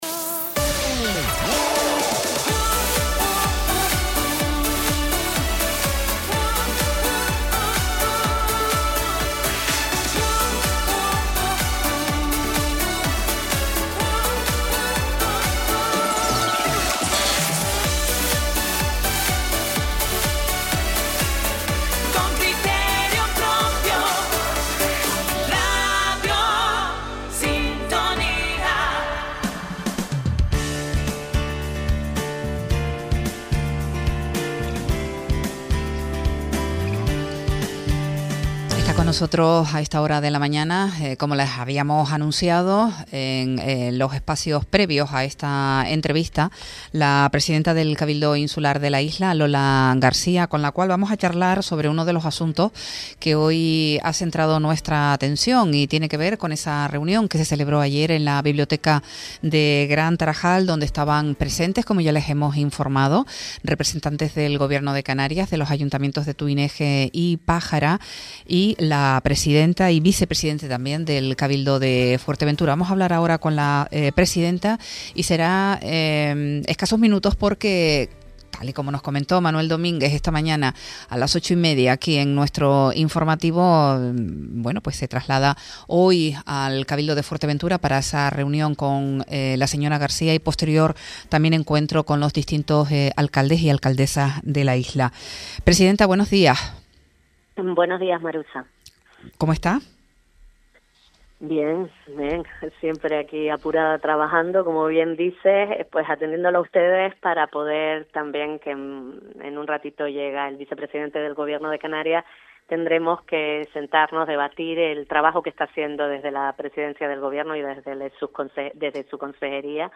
Reunión celebrada la pasada tarde en la Biblioteca de Gran Tarajal para informar a los vecinos del tramo de la autovía del Eje Norte-Sur, en el tramo Cuchillete-Matas Blancas.
Entrevistas